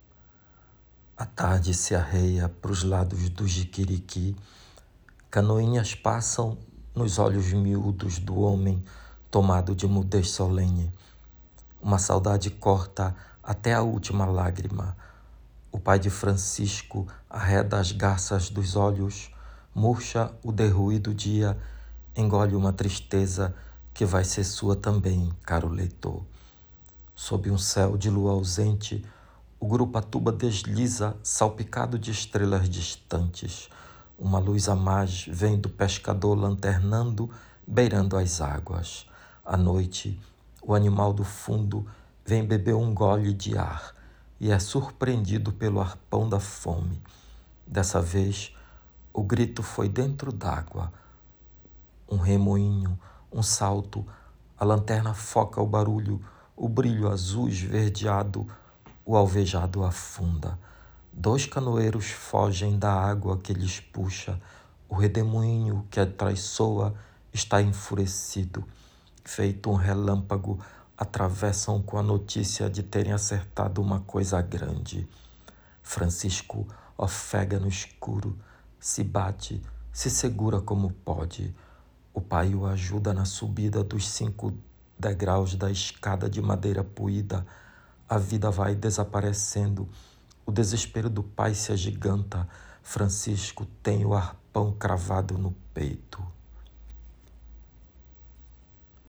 lendo um trecho do conto